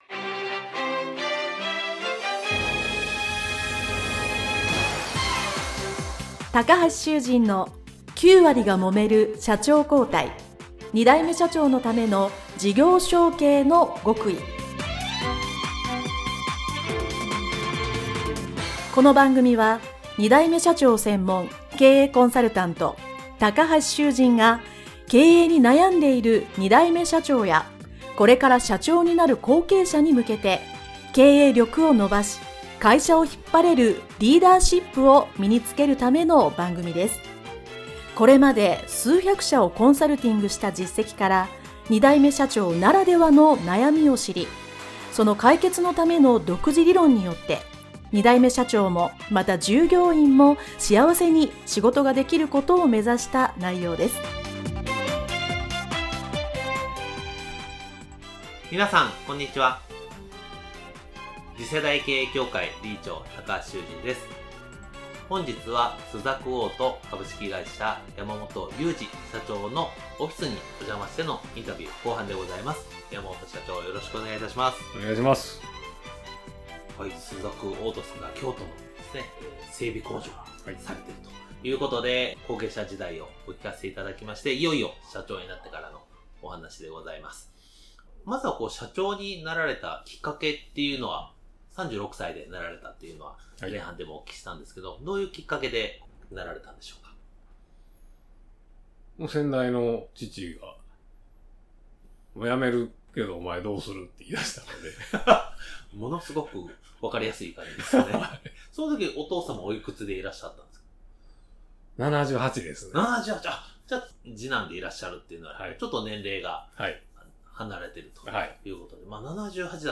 インタビュー後編